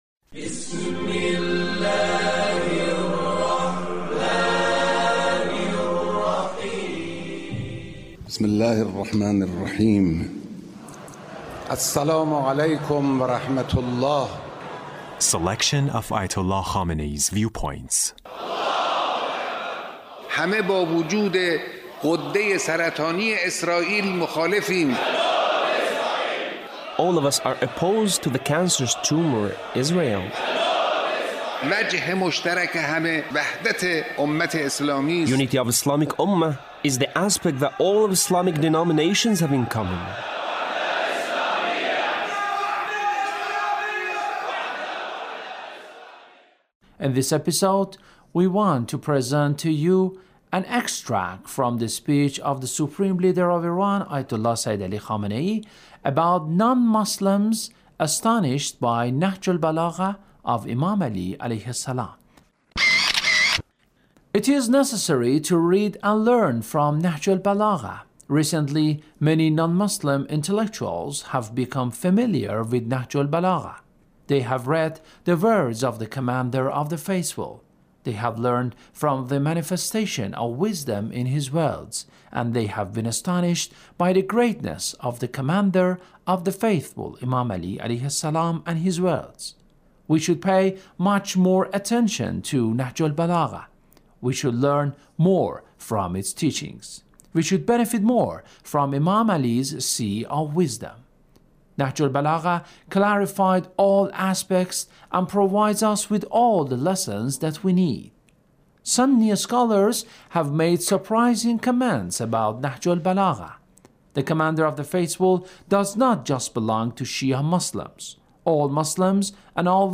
Leader's Speech (1926)